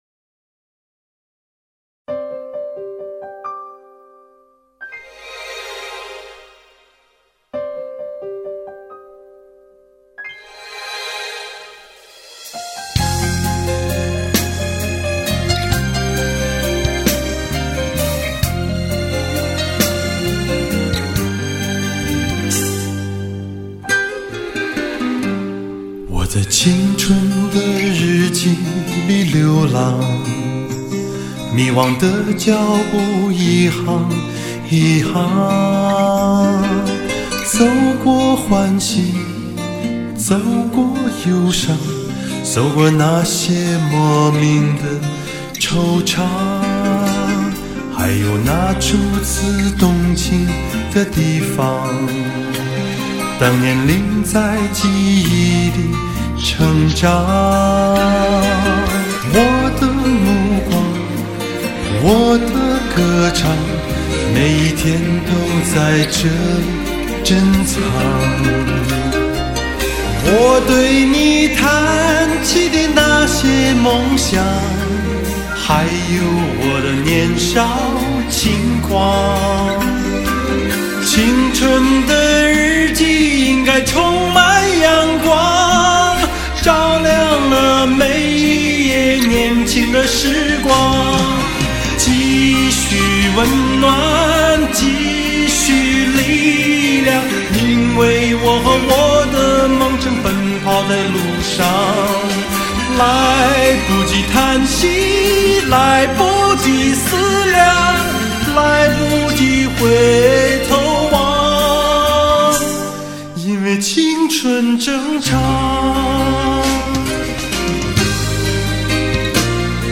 翻唱